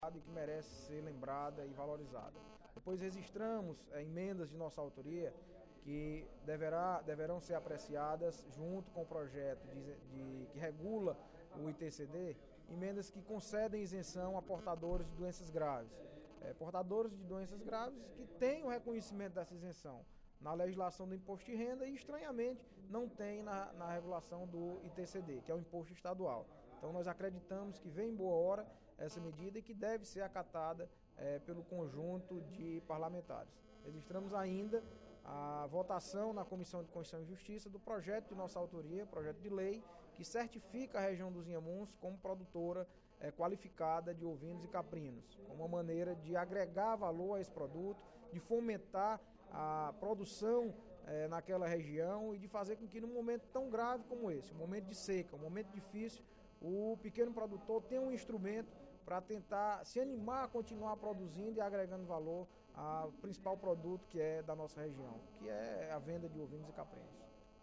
O deputado Audic Mota (PMDB) lamentou,  em pronunciamento no primeiro expediente da sessão plenária desta sexta-feira (19/06), o falecimento do ex-deputado Federal Paes de Andrade .